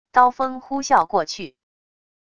刀锋呼啸过去wav音频